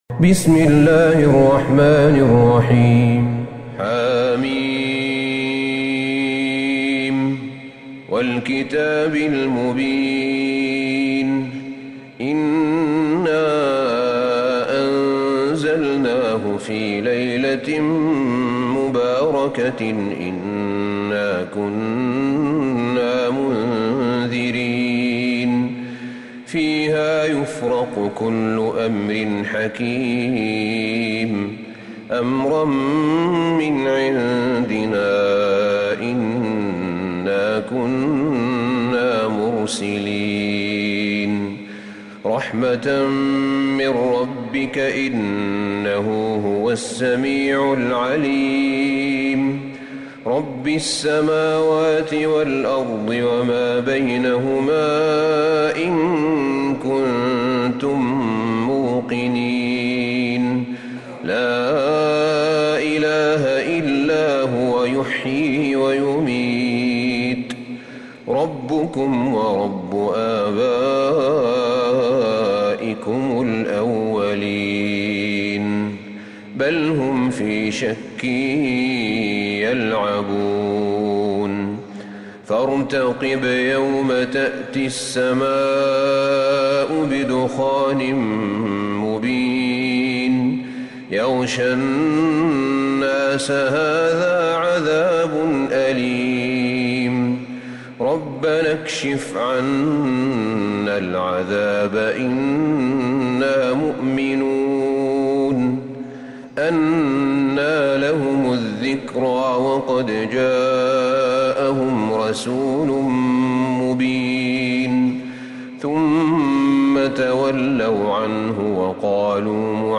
سورة الدخان Surat AdDukhan > مصحف الشيخ أحمد بن طالب بن حميد من الحرم النبوي > المصحف - تلاوات الحرمين